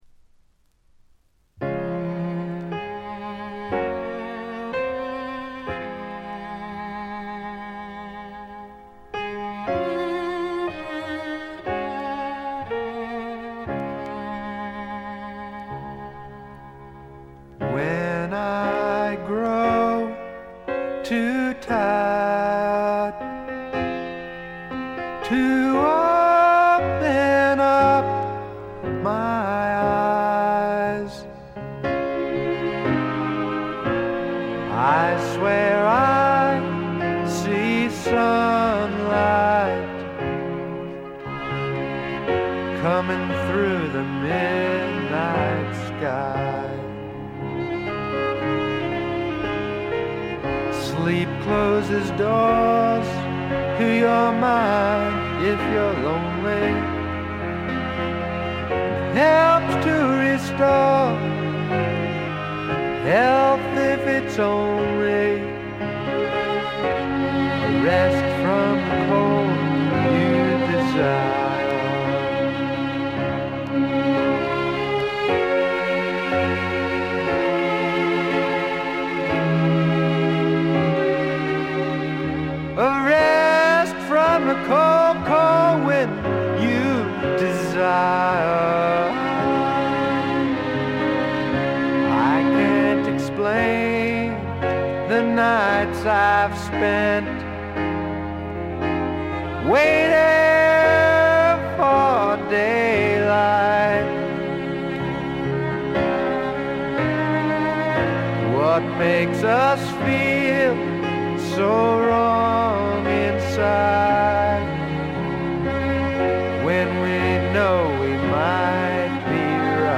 ほとんどノイズ感無し。
素晴らしい楽曲と素朴なヴォーカル。フォーキーな曲から軽いスワンプ風味を漂わせる曲までよく練られたアレンジもよいです。
試聴曲は現品からの取り込み音源です。
Vocals, Piano, Harmonica, Acoustic Guitar